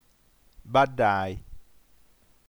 baddai vi vt baɖ.ɖà.i - [ba'ɖ:ai] ◊